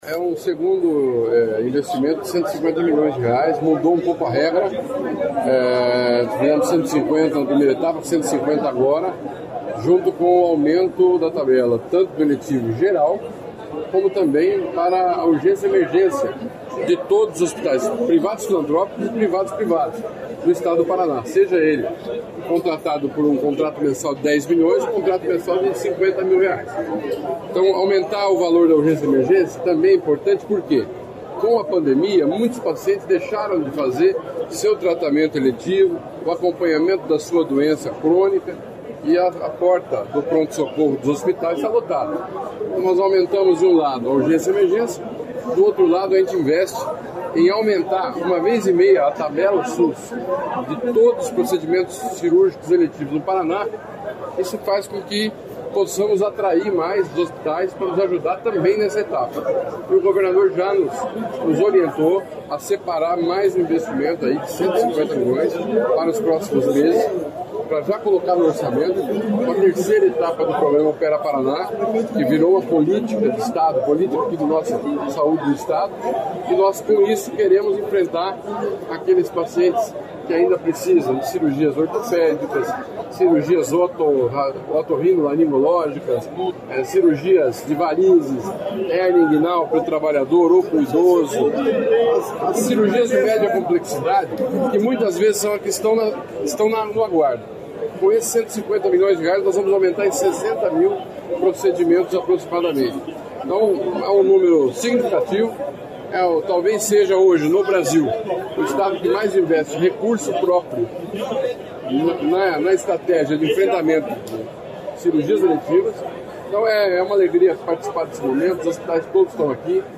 Sonora do secretário Estadual da Saúde, Beto Preto, sobre os R$ 403 milhões de aporte adicional anunciados para a área